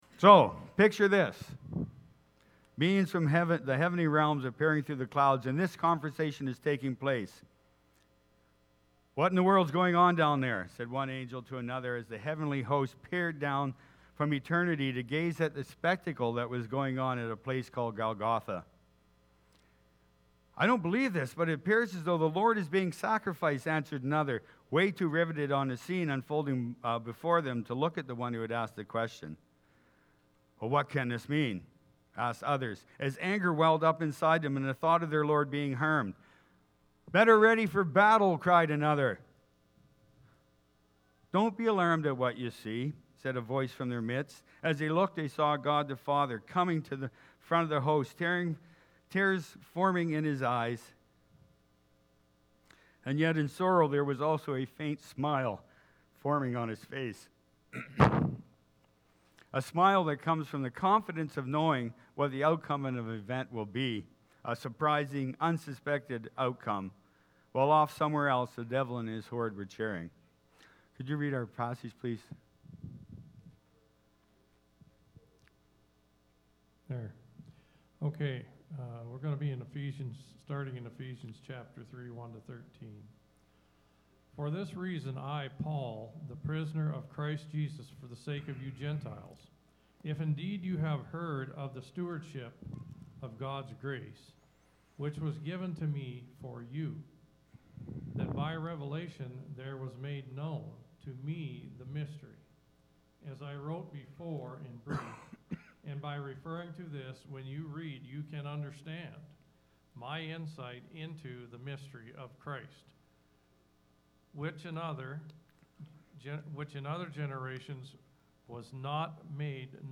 March-5th-2023-sermon-audio.mp3